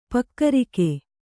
♪ pakkarike